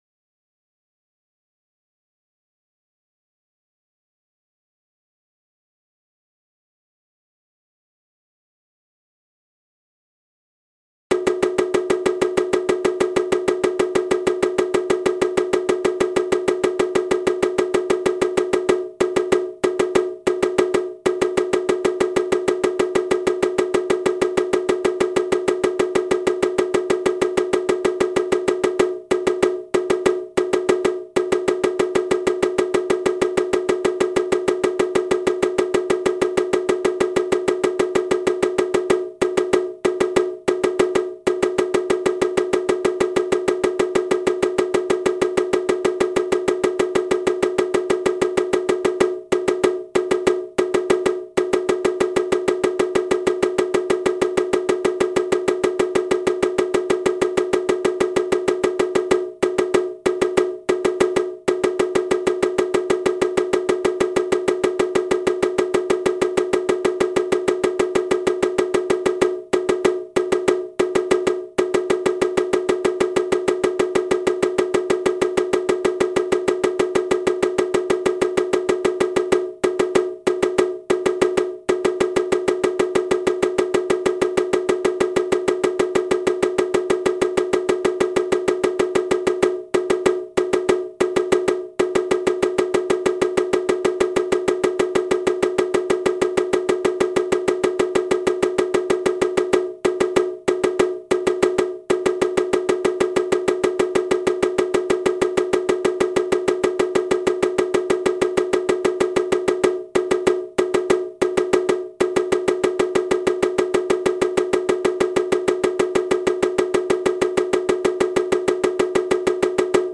hajime-drei-95bpm-1000ms.ogg (1.1M - updated 1 year, 2 months ago)